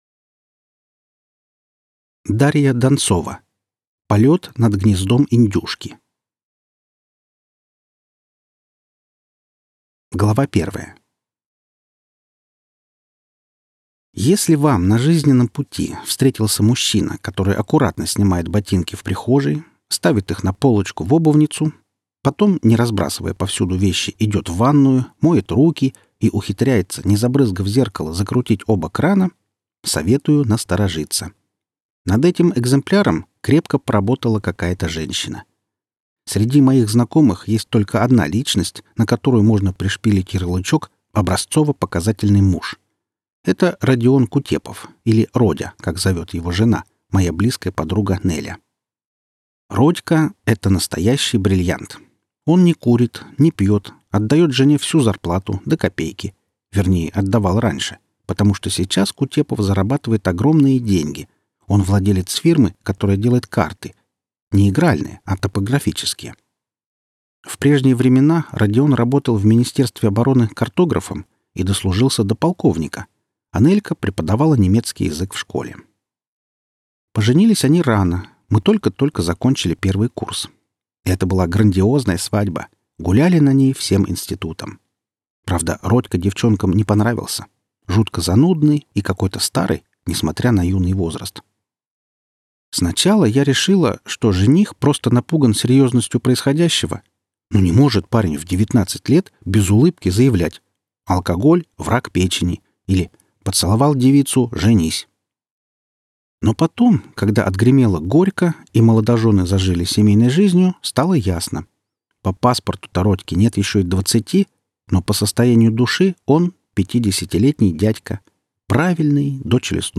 Аудиокнига Полет над гнездом индюшки - купить, скачать и слушать онлайн | КнигоПоиск